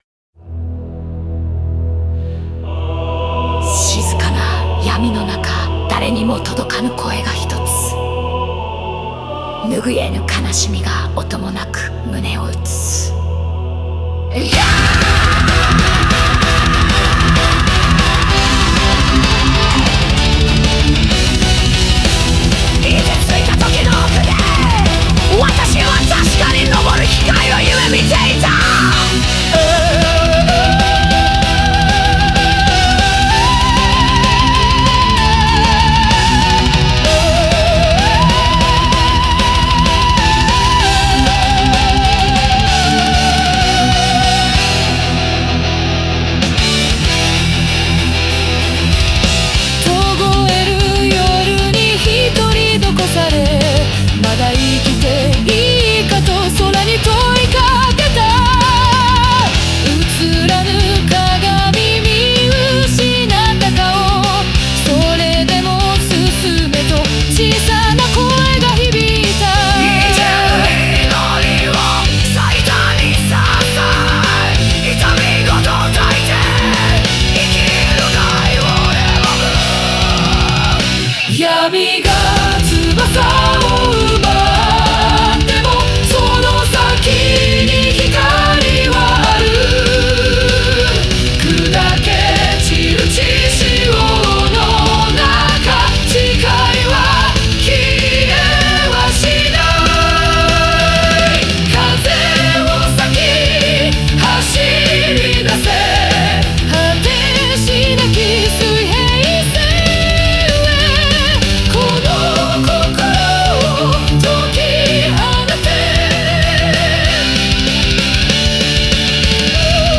Melodic Power Metal